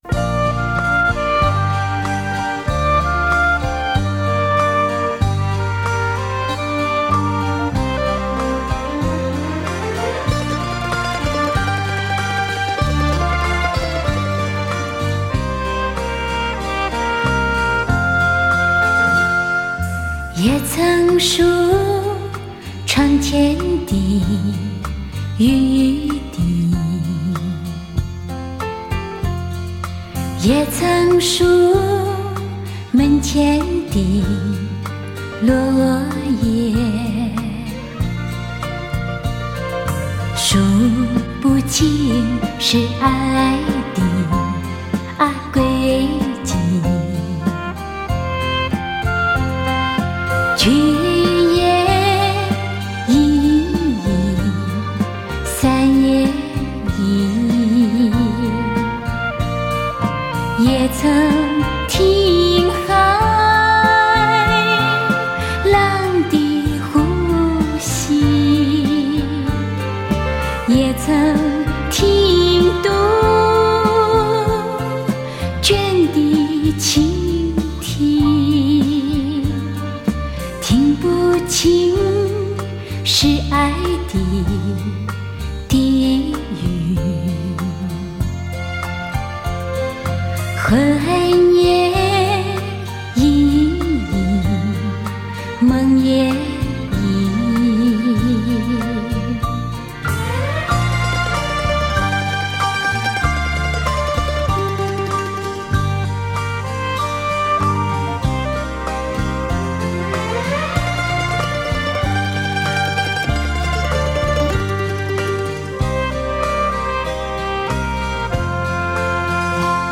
黑胶唱片原音复制
马来西亚黑胶复刻版